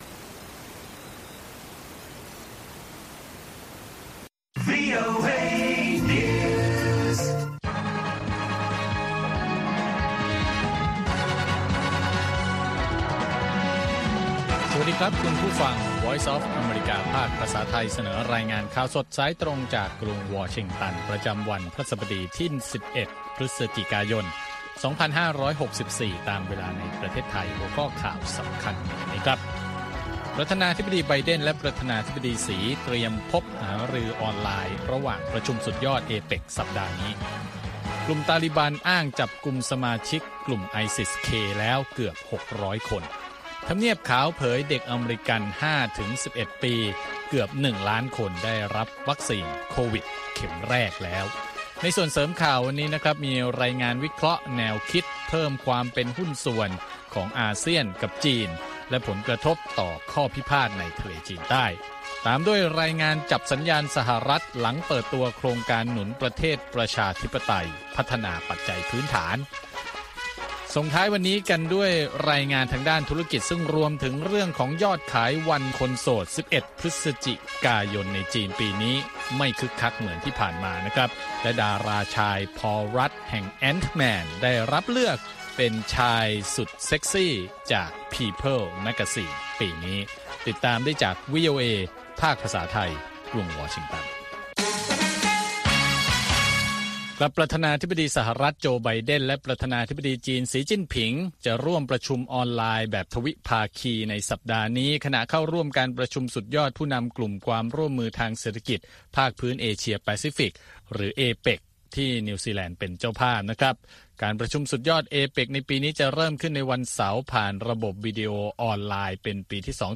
ข่าวสดสายตรงจากวีโอเอ ภาคภาษาไทย ประจำวันพฤหัสบดีที่ 11 พฤศจิกายน 2564 ตามเวลาประเทศไทย